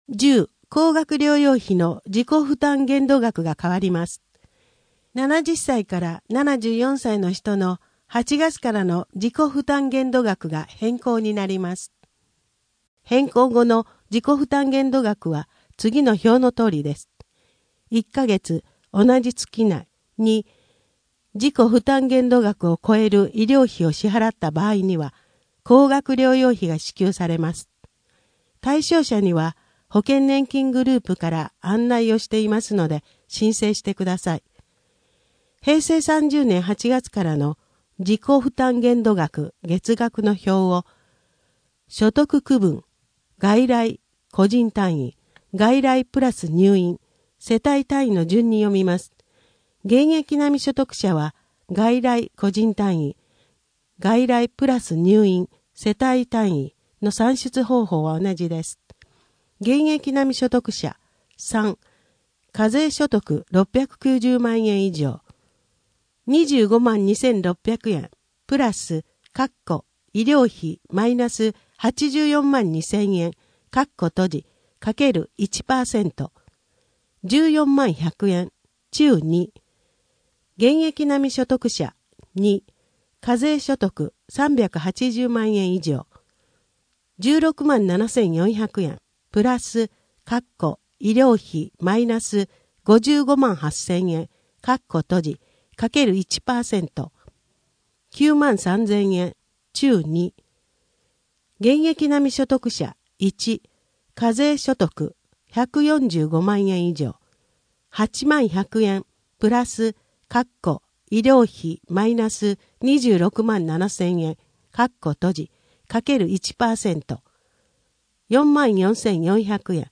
声の「広報はりま」7月号
声の「広報はりま」はボランティアグループ「のぎく」のご協力により作成されています。